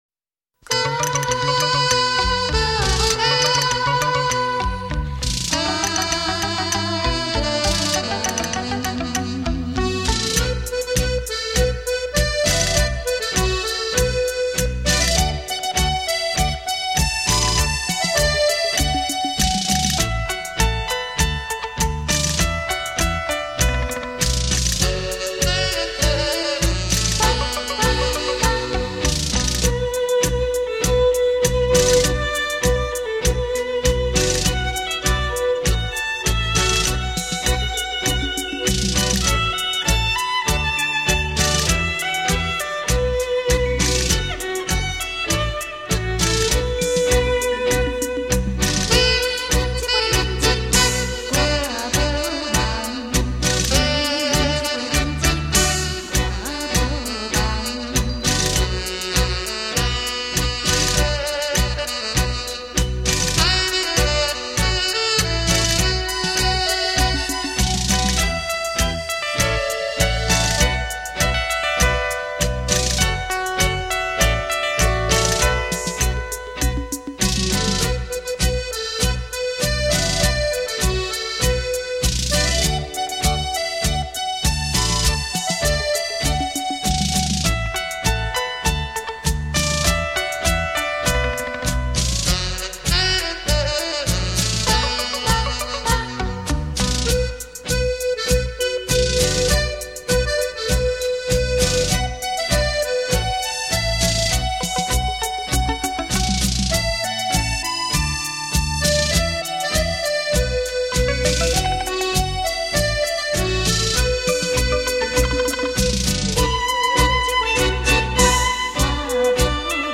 重新诠释并融入舞曲风格保证令人耳目一新